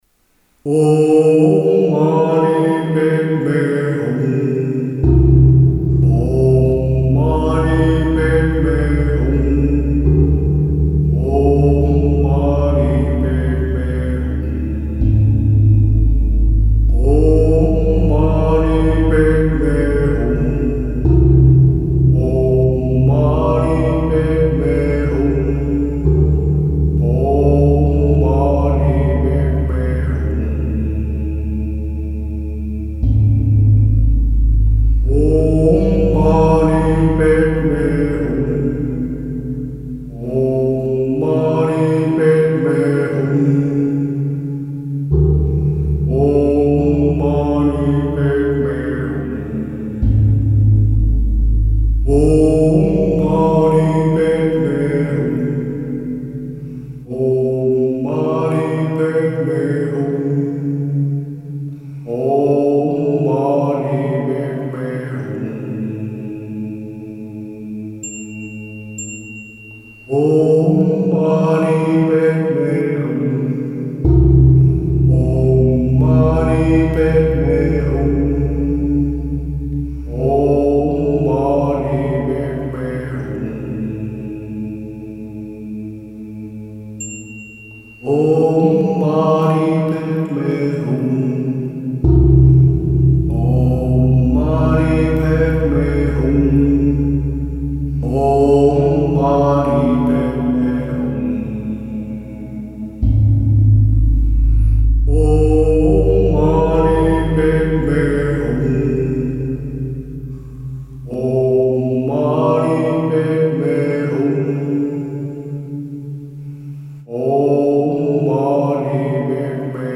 for voice, Vietnamese gongs, Japanese bells and taiko